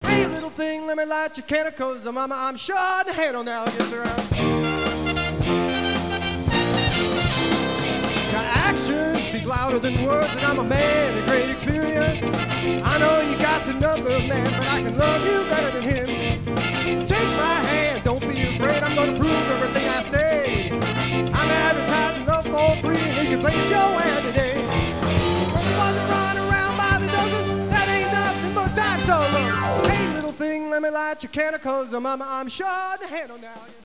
Lead Vocal